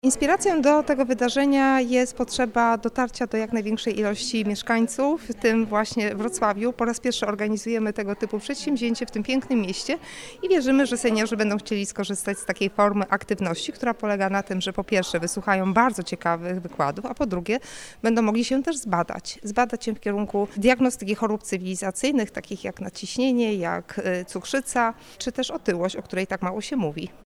01_konferencja-zdrowie.mp3